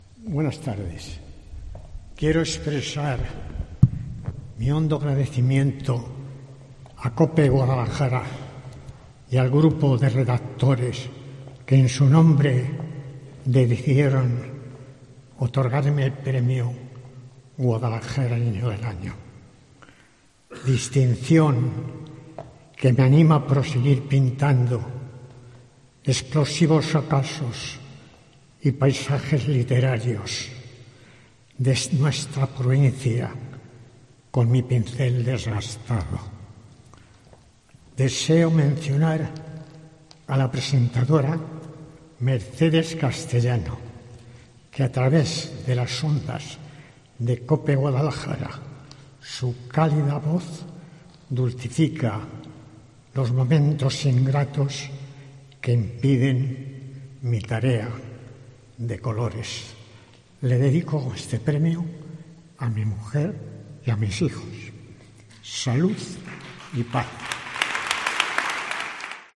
Discurso de agradecimiento